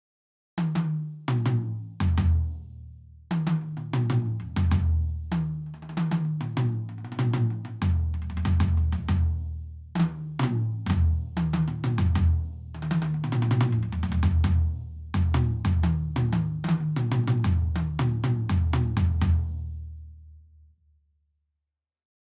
Die Toms haben generell viel Attack, auch in niedrigeren Lautstärken.
Maple Toms
roland_td-17k-l_test__maple_toms.mp3